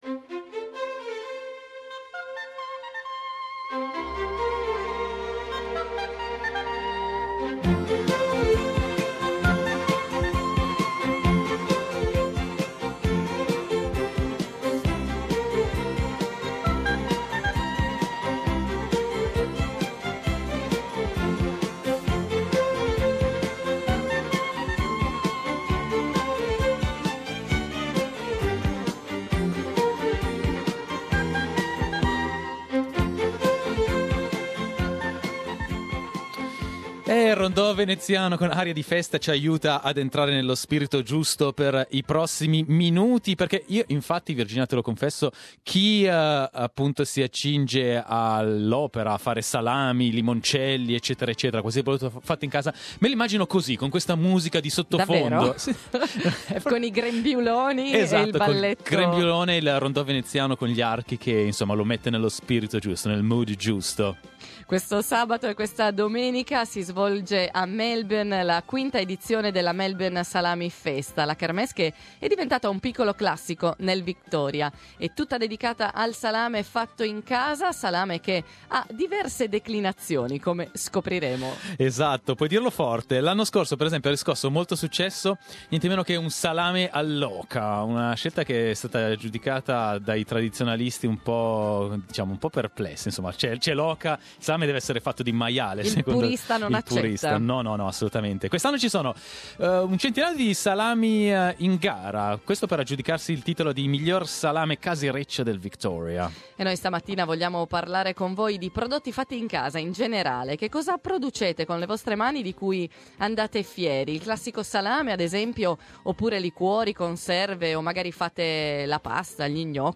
Salumi, pasta, passata, marmellate: voi cosa producete in casa? Lo abbiamo chiesto questa mattina ai nostri ascoltatori, e abbiamo scoperto che nella comunità italiana la tradizione di preparare da mangiare in casa è viva e vegeta.